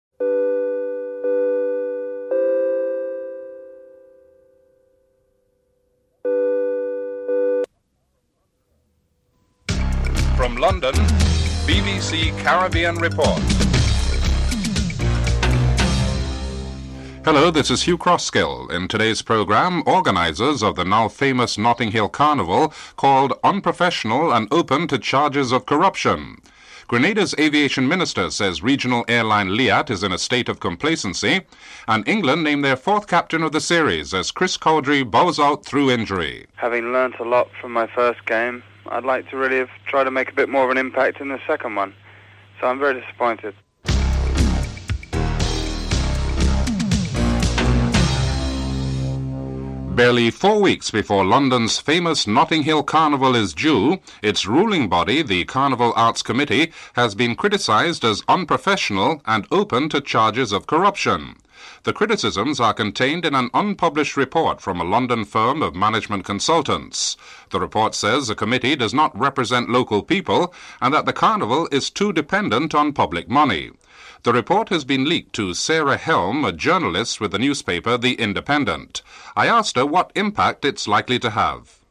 Finally, injured English cricket captain Chris Cowdrey speaks about his injury and his disappointment at not being able to play in the fifth and final test match between England and the West Indies
3. Caribbean airline LIAT comes under fire from Grenada’s aviation minister Dr. Keith Mitchell, for what he sees as the airline’s high level of complacency stemming from its monopolistic position in the region’s airline industry.